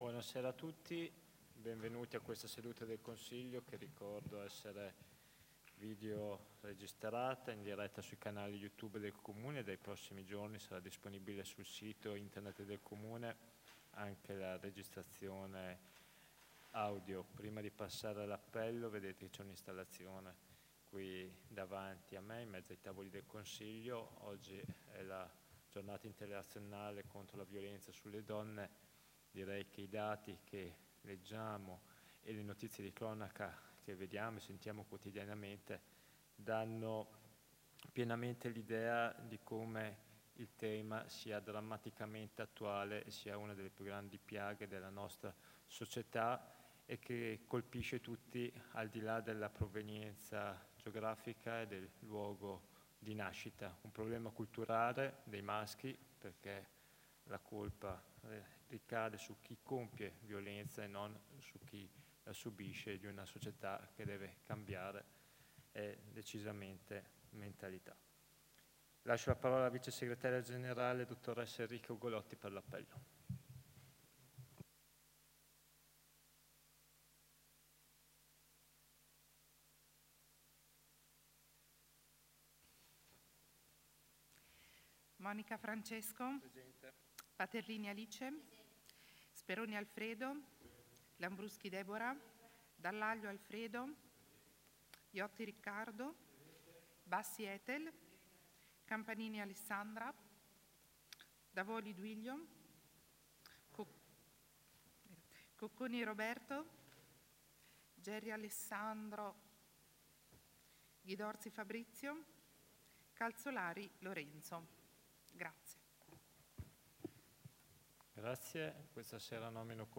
Seduta del Consiglio Comunale del 25/11/2025